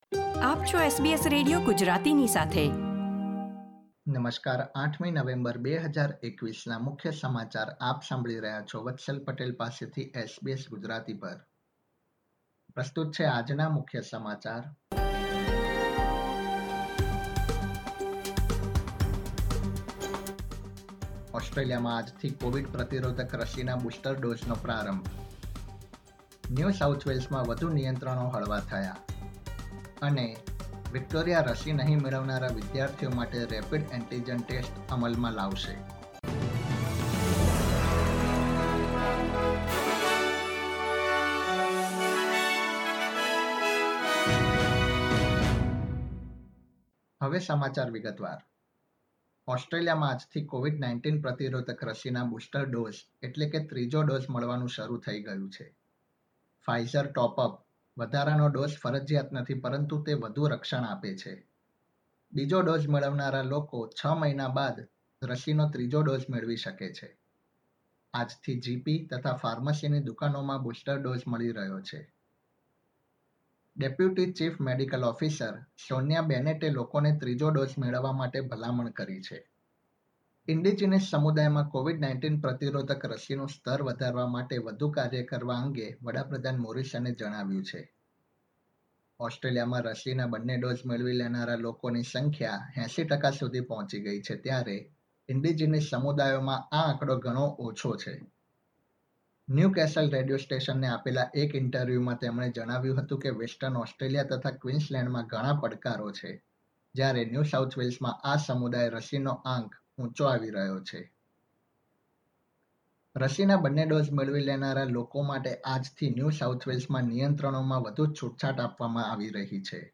SBS Gujarati News Bulletin 8 November 2021
gujarati_0811_newsbulletin.mp3